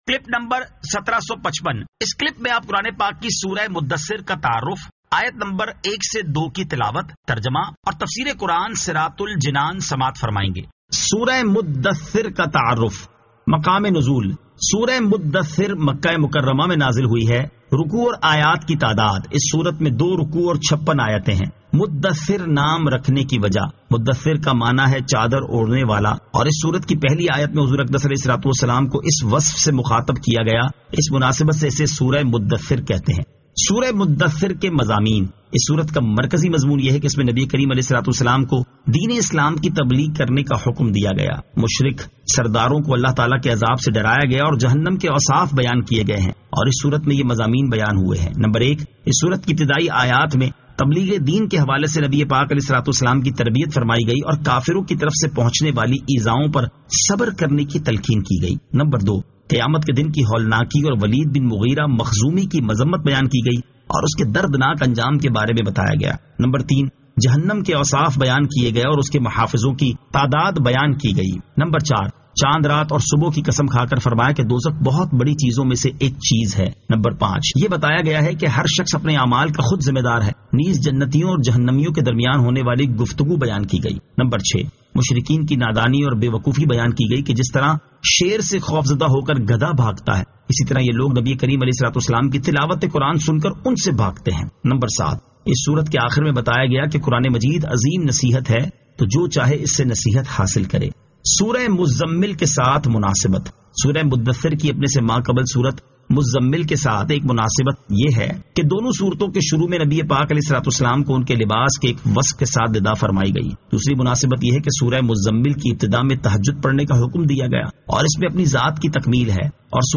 Surah Al-Muddaththir 01 To 02 Tilawat , Tarjama , Tafseer